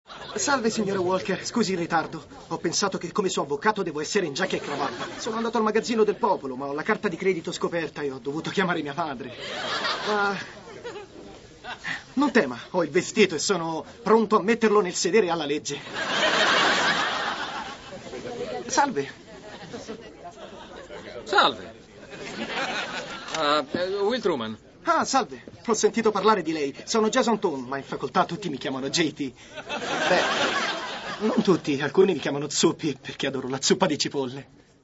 nel telefilm "Will & Grace", in cui doppia la guest-star Macaulay Culkin.